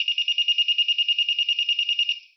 На этой странице собраны звуки металлодетекторов — от стандартных сигналов до вариаций при обнаружении разных металлов.
Звук активированного арочного металлодетектора